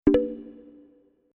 Bamboo Button Click 1.mp3